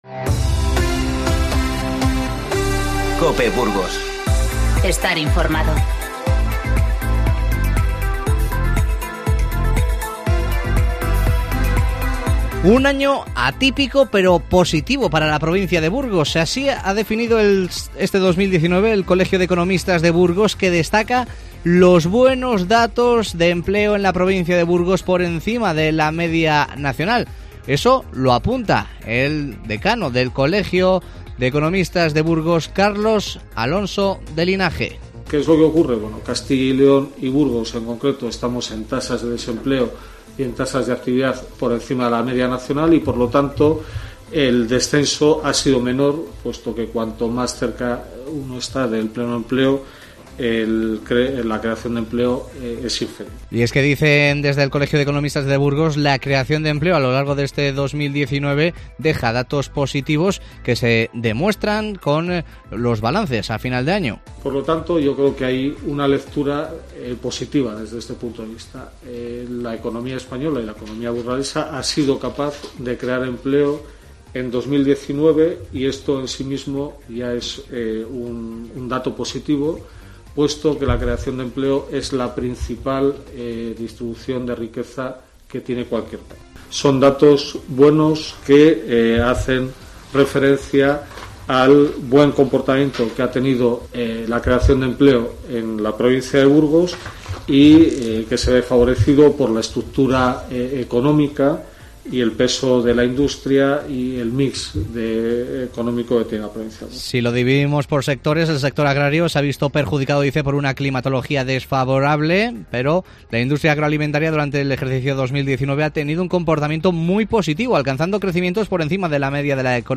INFORMATIVO Mediodía 27-12-19